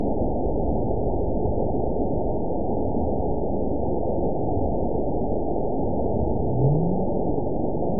event 917164 date 03/22/23 time 17:09:53 GMT (2 years, 1 month ago) score 9.70 location TSS-AB01 detected by nrw target species NRW annotations +NRW Spectrogram: Frequency (kHz) vs. Time (s) audio not available .wav